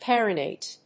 perennate.mp3